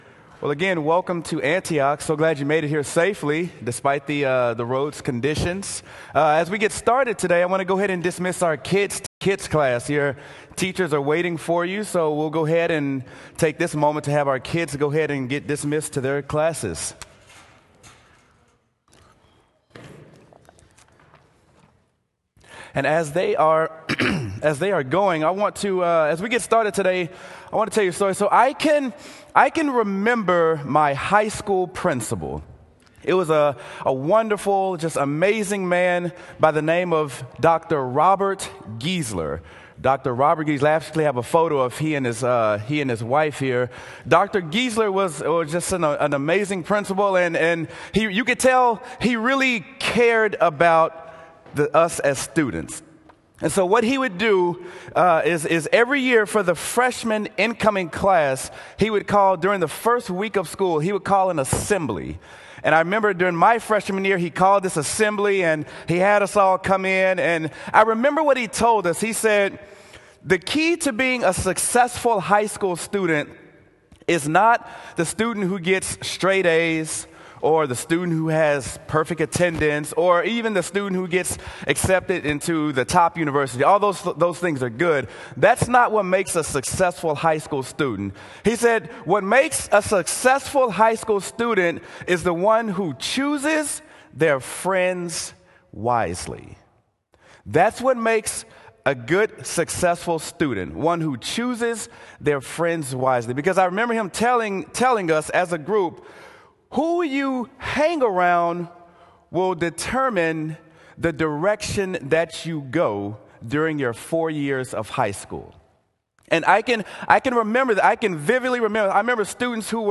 Sermon: Mark: Guard Your Life